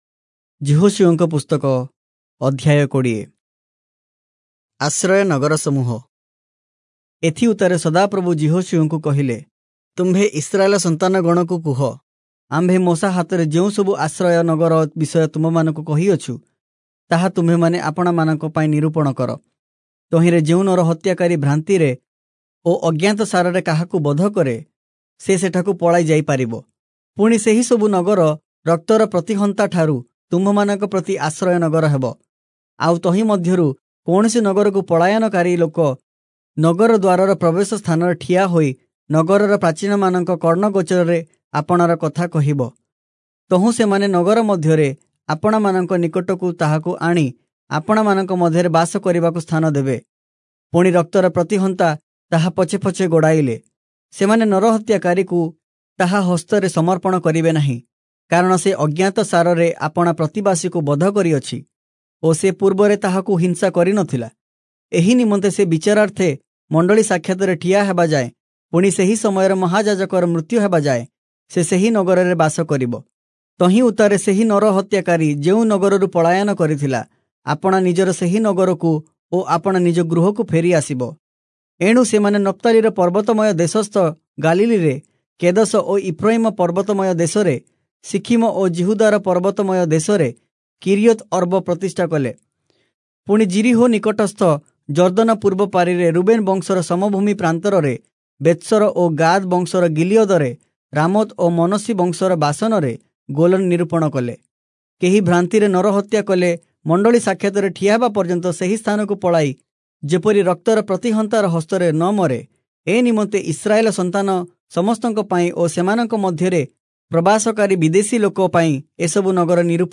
Oriya Audio Bible - Joshua 14 in Irvor bible version